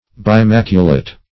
Search Result for " bimaculate" : The Collaborative International Dictionary of English v.0.48: Bimaculate \Bi*mac"u*late\, a. [Pref. bi- + maculate, a.]